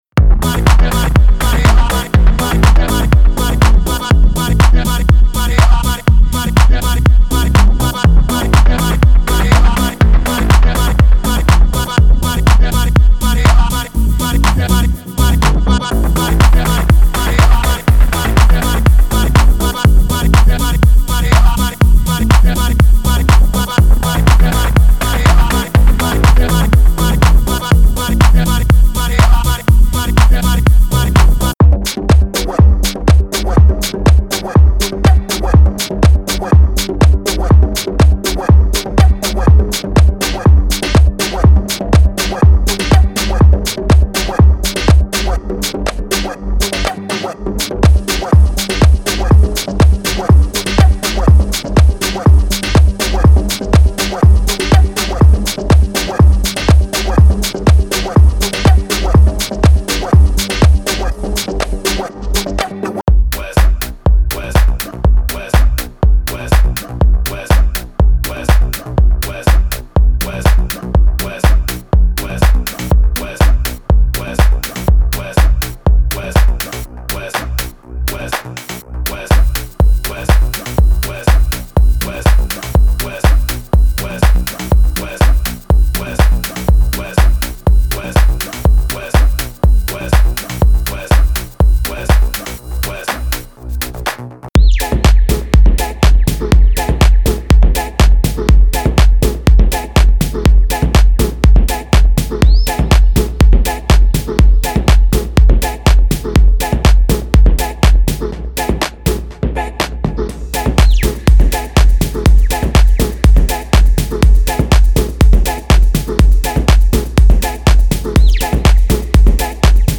这是一幢深沉，旋律的房子，正向着前瞻性的制作人倾诉，发出清晰，纯净的声音。